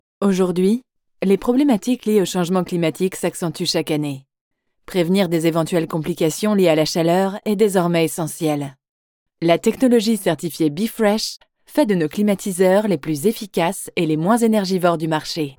Jong, Natuurlijk, Vriendelijk, Zakelijk
Explainer